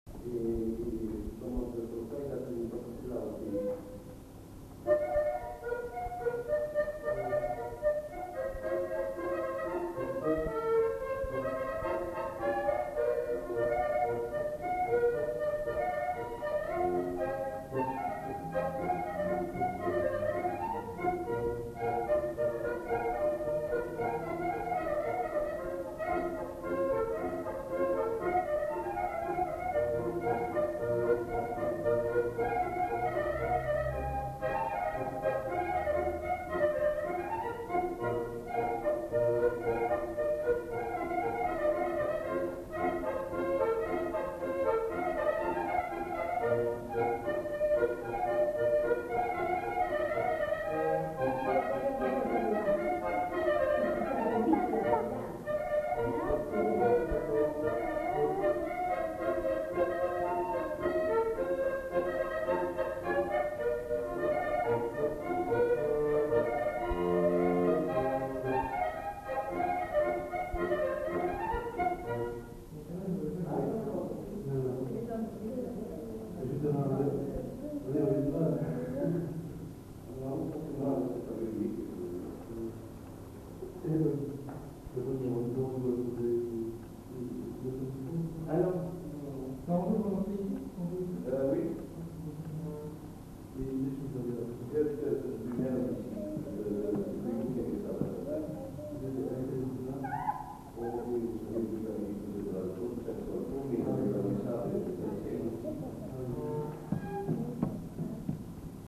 enquêtes sonores
Polka